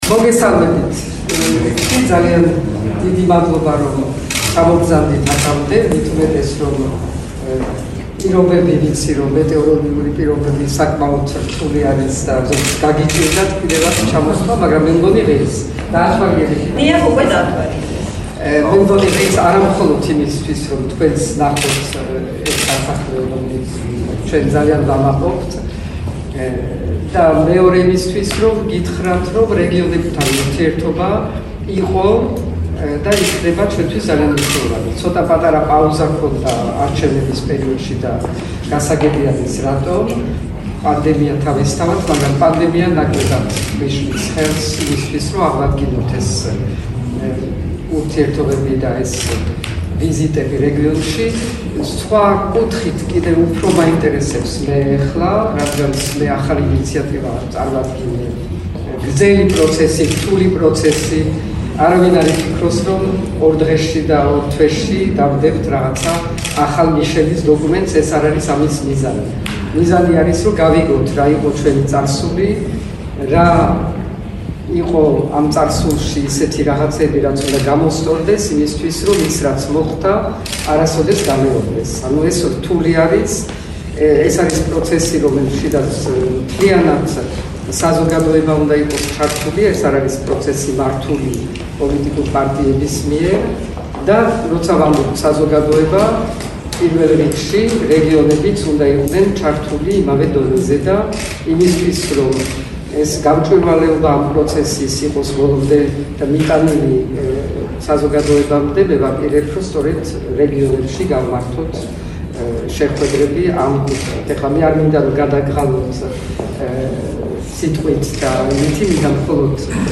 პრეზიდენტის ორბელიანის სასახლეში, საქართველოს პრეზიდენტმა სალომე ზურაბიშვილმა დღეს, რეგიონულ მედიას უმასპინძლა. შეხვედრაზე რადიო ჰერეთიც იმყოფებოდა.
მოისმინეთ ზურაბიშვილის ხმა